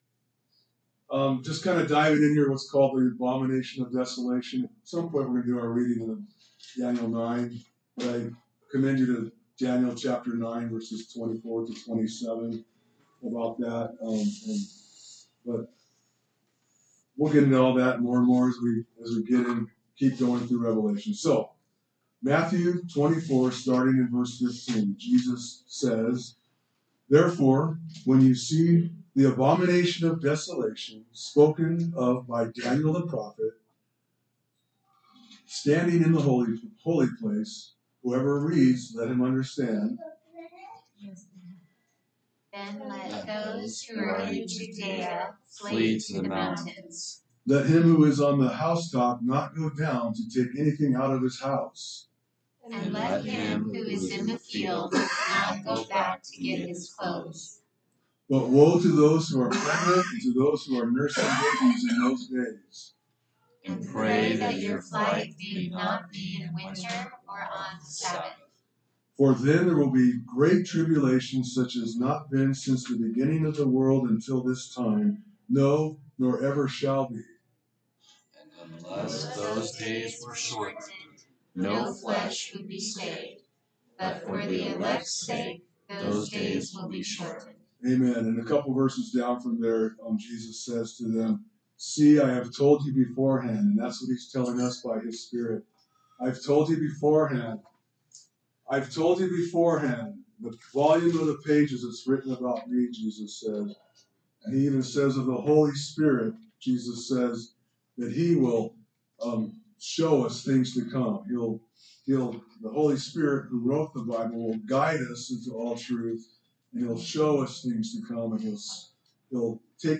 A message from the series "Revelation."